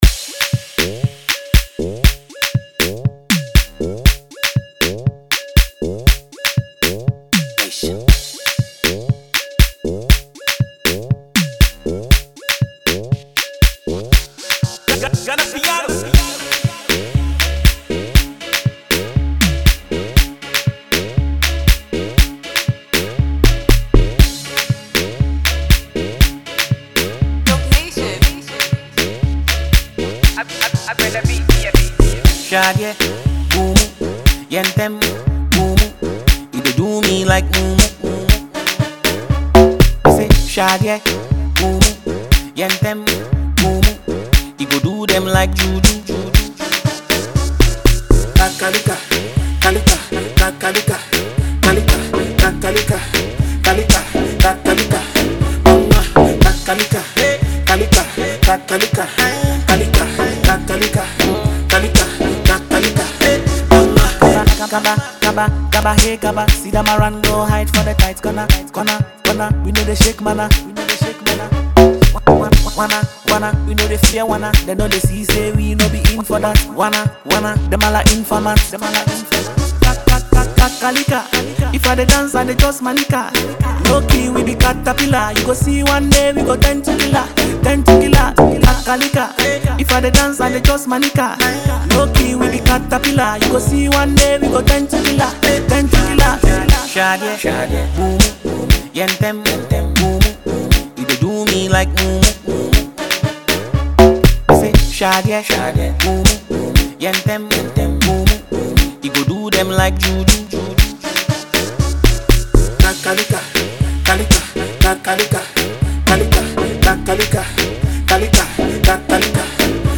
is a lively and energetic track
engaging beat